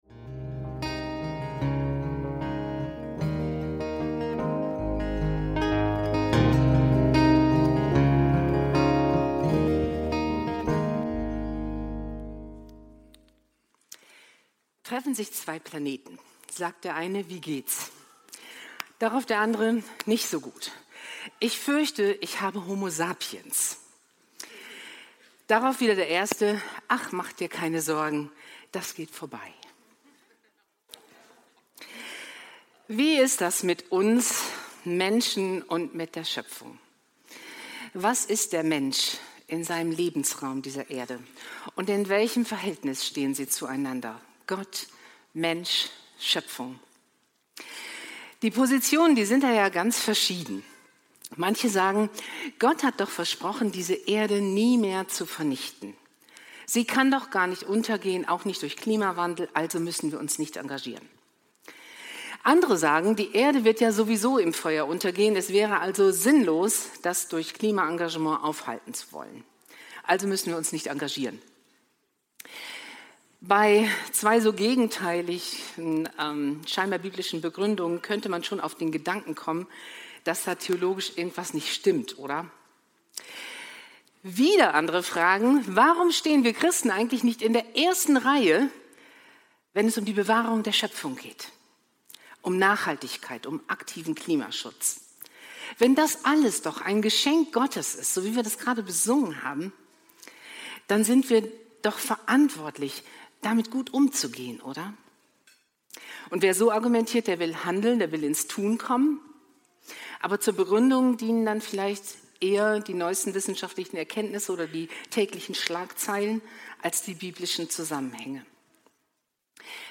Leben in der Schöpfungsgemeinschaft – Gestalten: Welche Rolle der Mensch in der Schöpfung hat ~ FeG Bochum Predigt Podcast